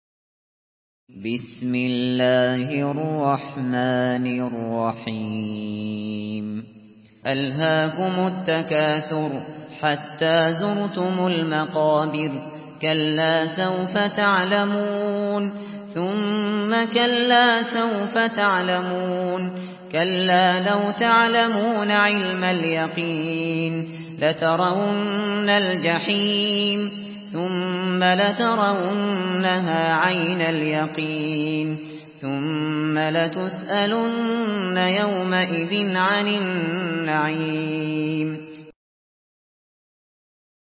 Surah At-Takathur MP3 in the Voice of Abu Bakr Al Shatri in Hafs Narration
Murattal